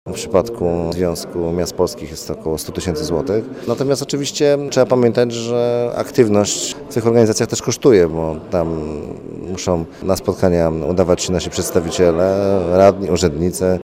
– tłumaczy prezydent Szczecina Piotr Krzystek.